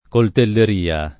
coltelleria [ kolteller & a ] s. f.